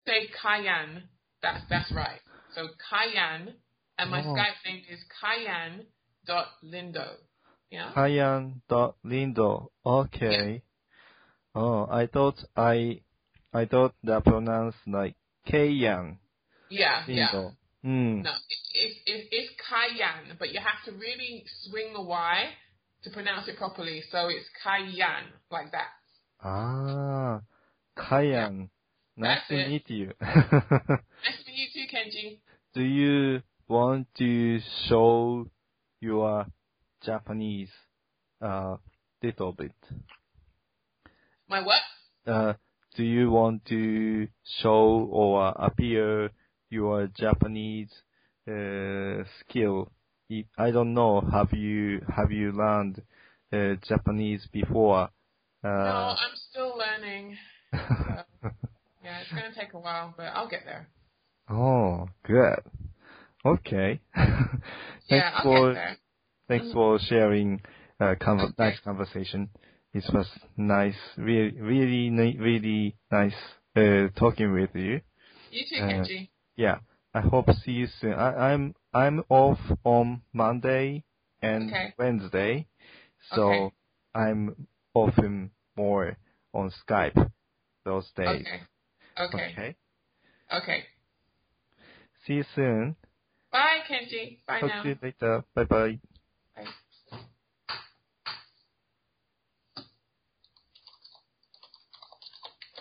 Relaxed Chat